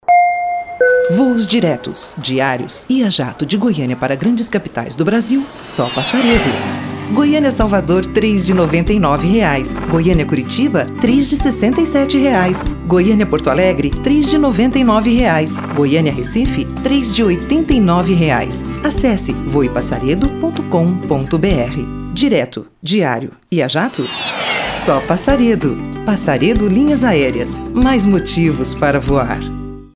Voiceover Portugues Brasileiro, voiceover talent.
Sprechprobe: eLearning (Muttersprache):